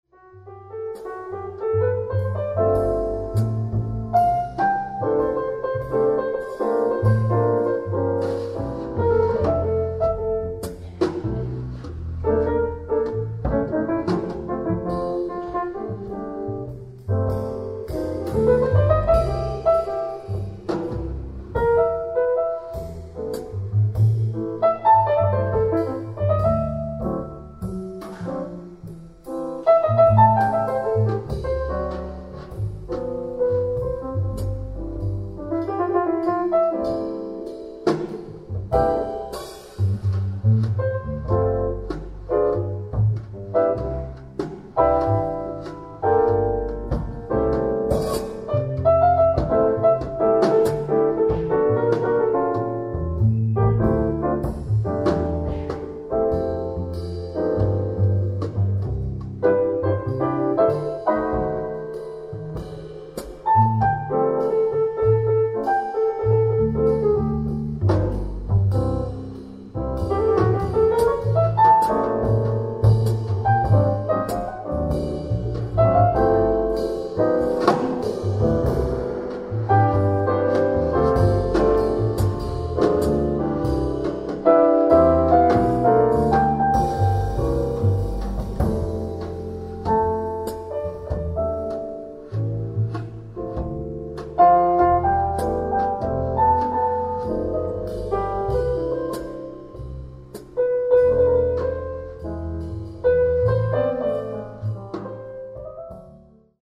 ライブ・アット・ザ・バービカン、ロンドン 06/24/2025
※試聴用に実際より音質を落としています。